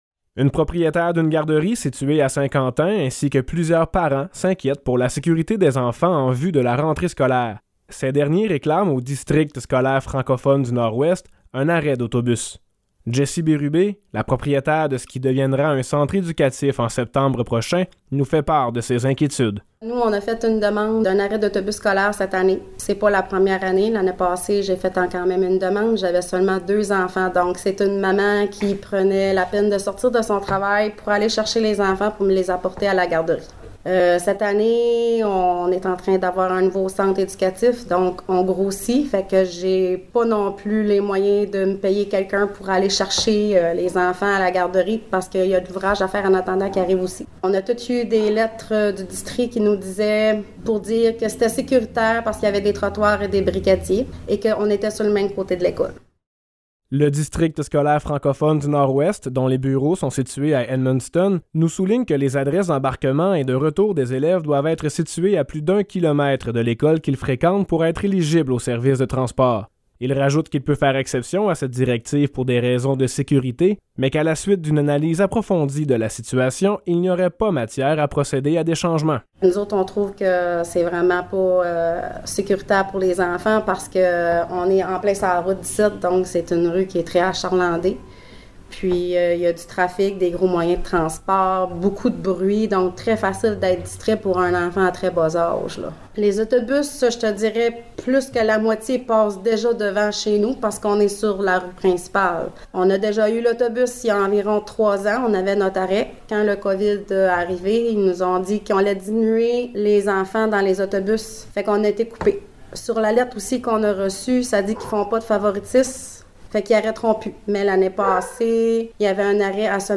reportage-refu-transport-scolaire.mp3